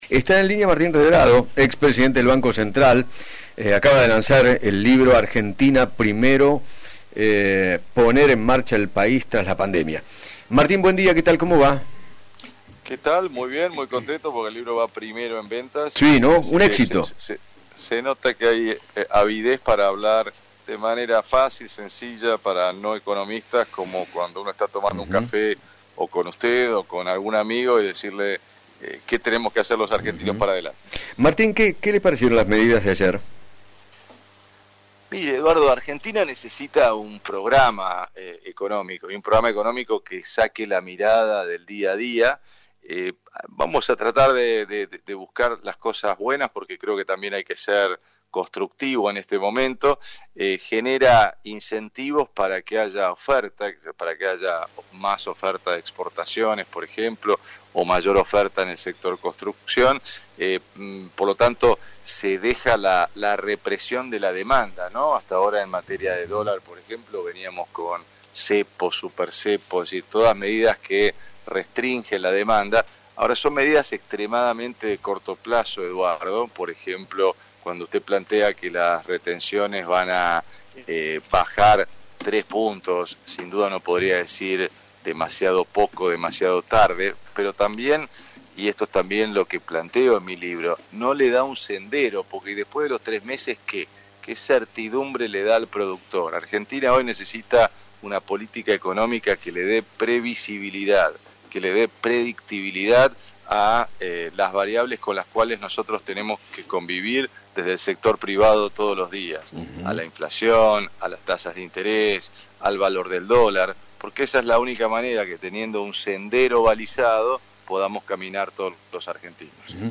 El economista Martín Redraro dialogó con Eduardo Feinmann sobre la nuevas medidas económicas que anunció el Gobierno y explicó que “son de corto plazo. Hay que estabilizar la economía y modernizar nuestra estructura productiva”.